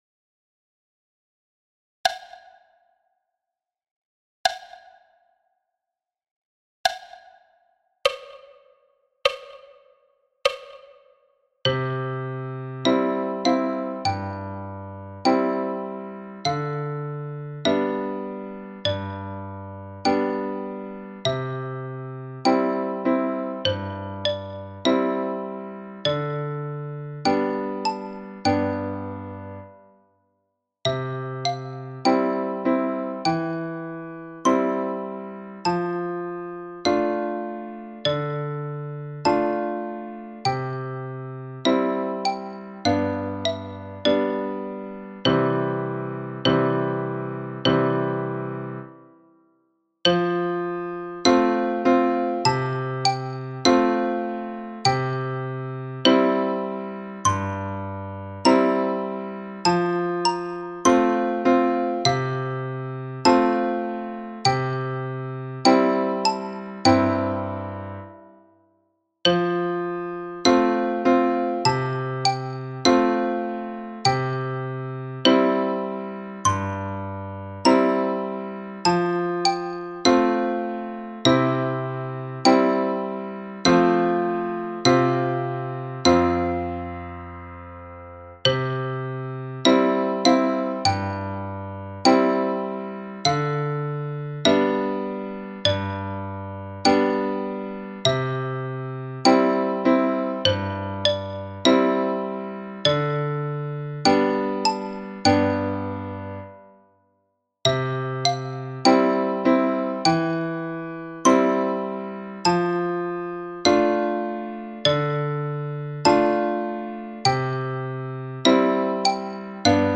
Danse du canard – tutti à 50 bpm